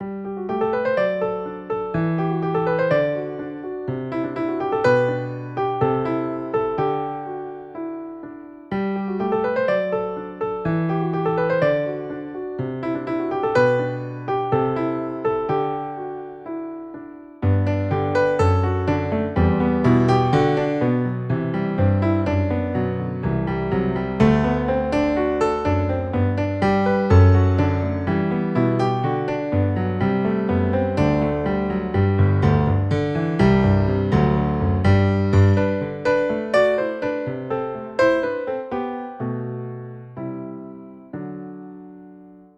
Piano 1 (bucle)
instrumento musical
piano
melodía
repetitivo
rítmico